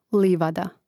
lìvada livada